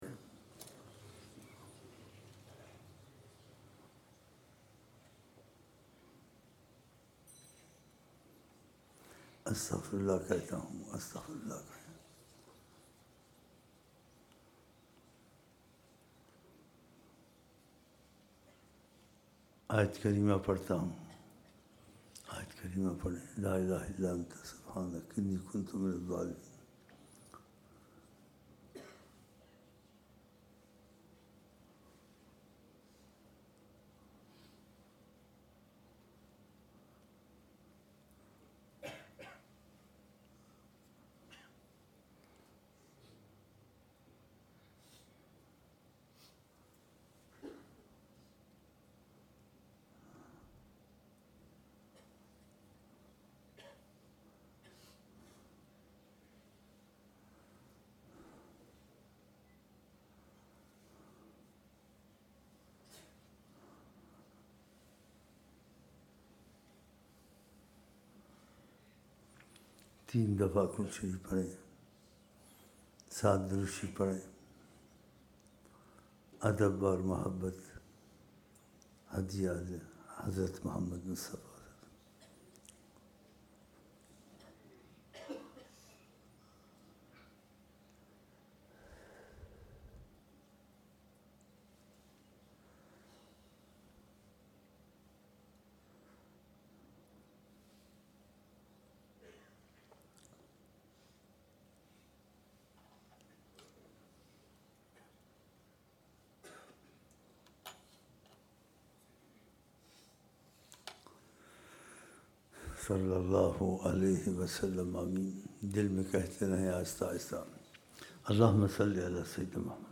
عشا محفل